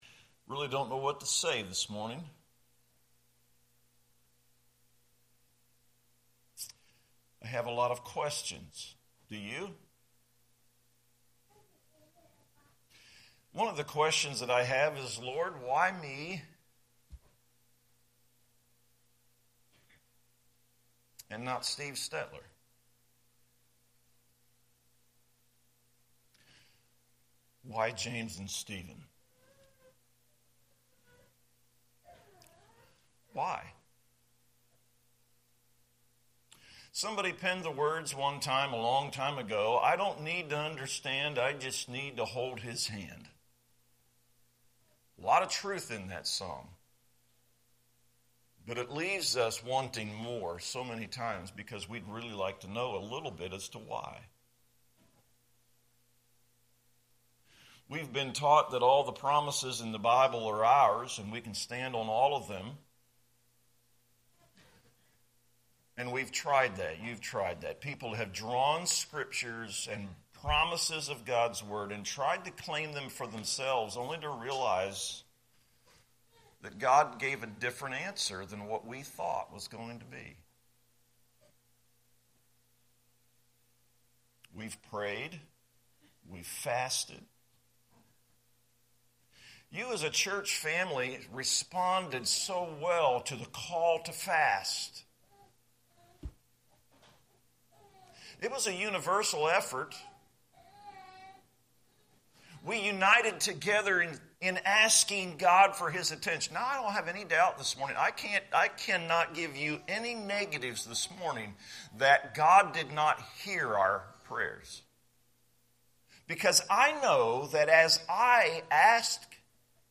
2026-2-1-am-sermon.mp3